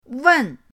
wen4.mp3